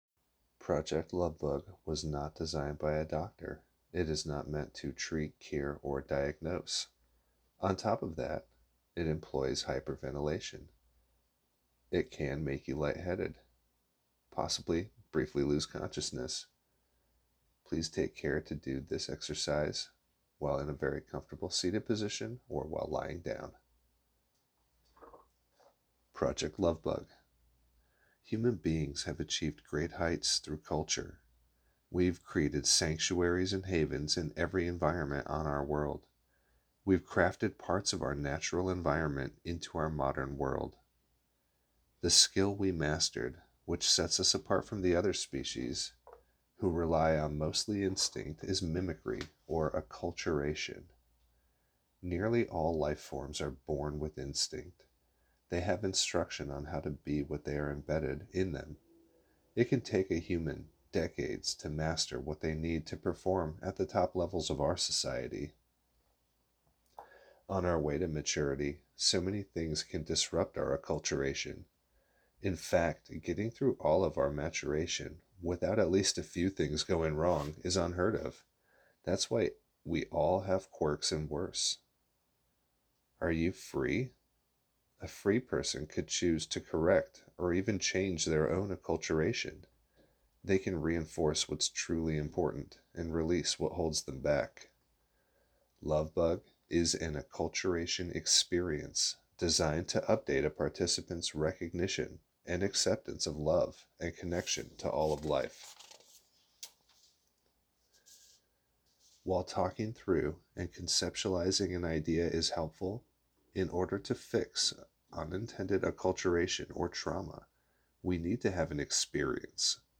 Project Lovebug . Listen to and participate with visualization and breathwork.